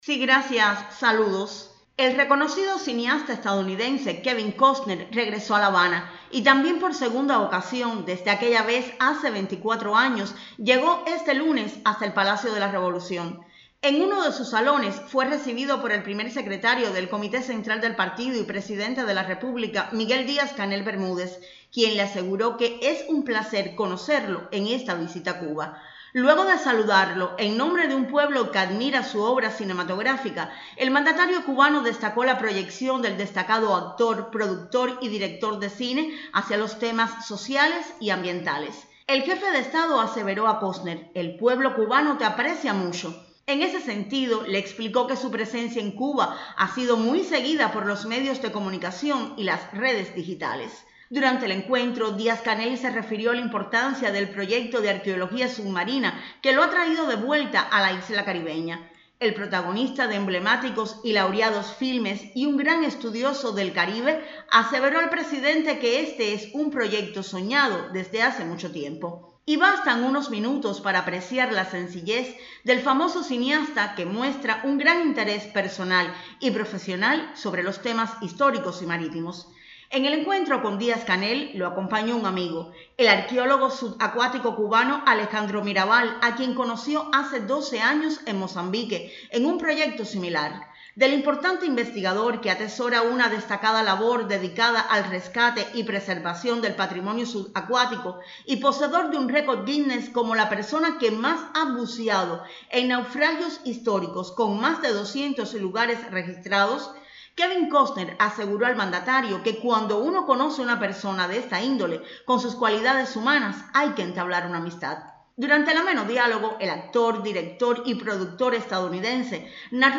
Este lunes en la tarde el Primer Secretario del Comité Central del Partido Comunista de Cuba y Presidente de la República, Miguel Díaz-Canel Bermúdez, dio la bienvenida en el Palacio de la Revolución al actor, director y productor estadounidense Kevin Costner, quien ha llegado por segunda vez a la Mayor de las Antillas enfrascado en una serie sobre arqueología submarina.